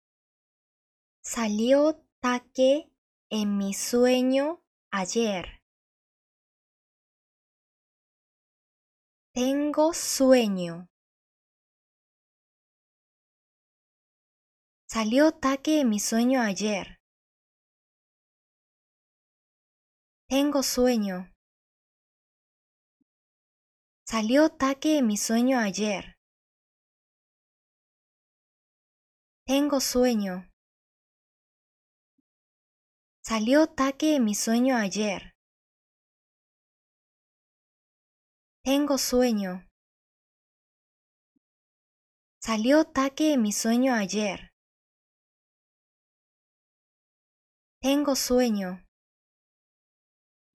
それでは、上の２つの例文のシャードーイングをしていきましょう！
★聞こえてきた通りに声に出してみる。